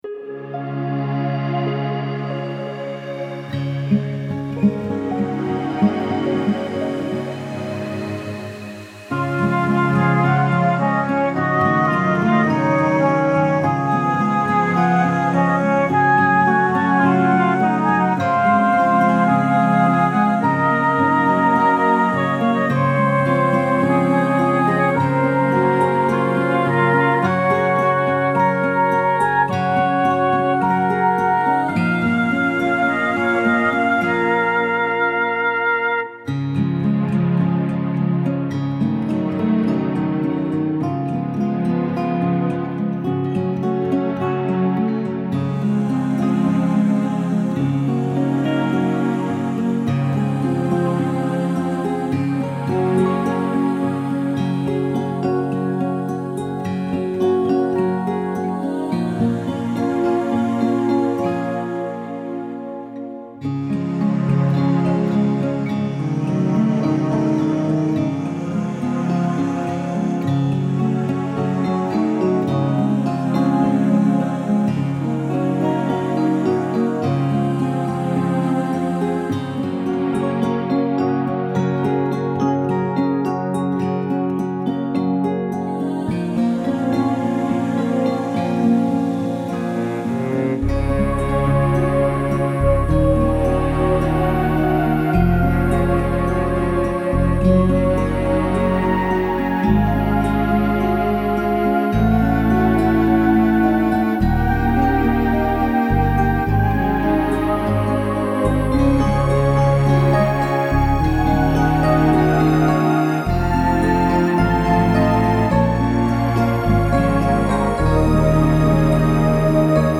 Then I recorded my guitar into those gorgeous arrangements in a new way.
I share below some recent examples of my guitar playing added into these revised arrangements: